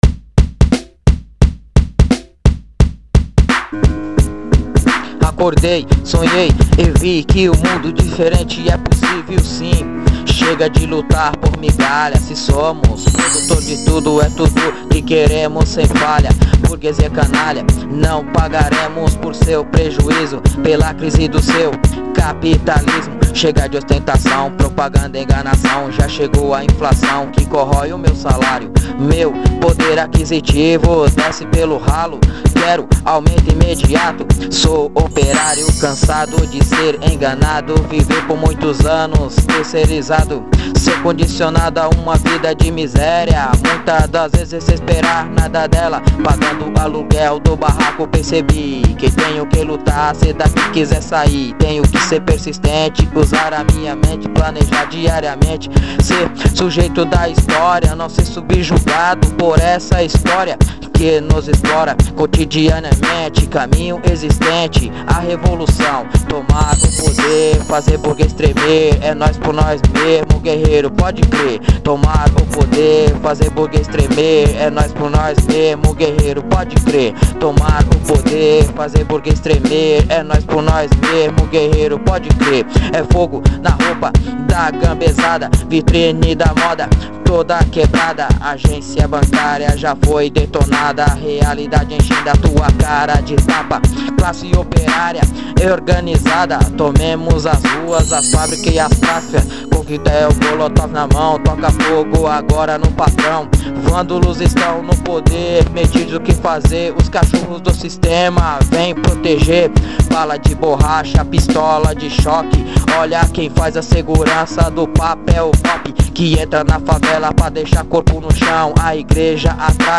Rap clasista desde Brasil
Obrero metalúrgico de Brasil nos envía estos rap clasistas por whatsapp